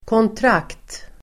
Uttal: [kåntr'ak:t]